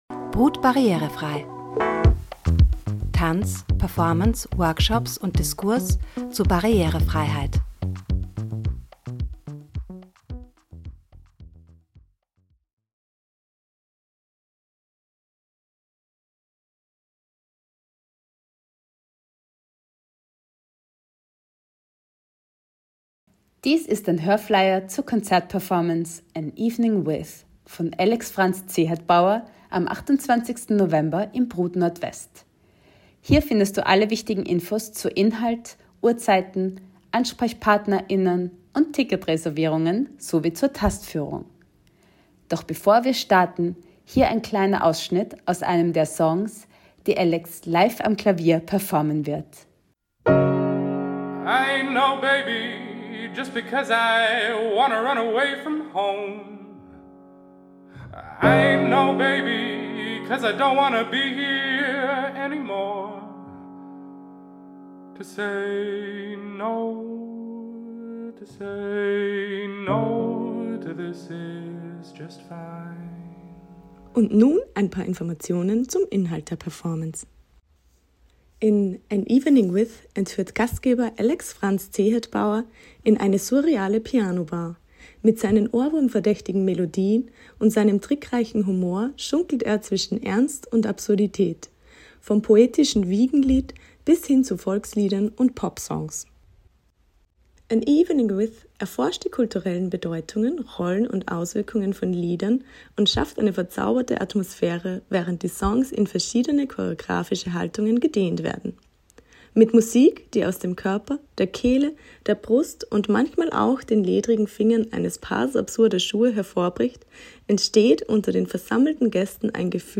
Die Konzert-Performance verwendet laute Sounds.
Performance / Konzert